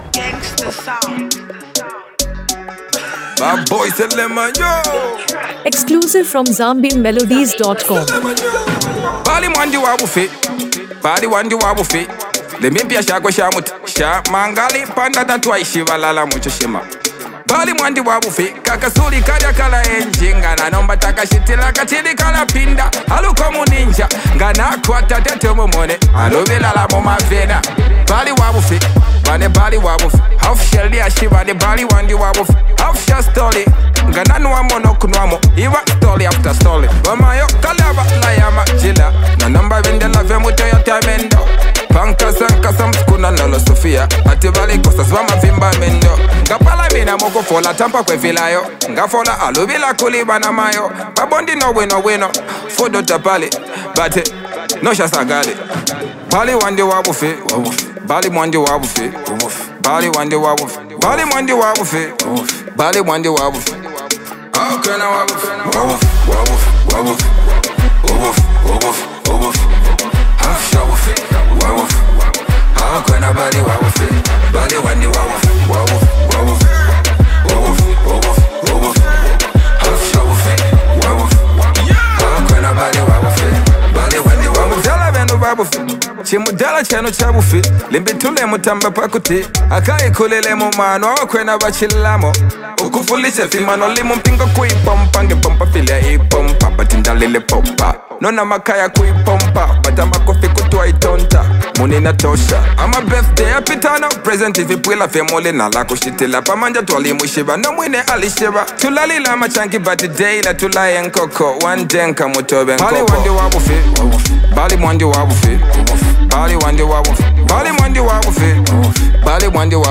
heartfelt storytelling with captivating beats
catchy and appealing sound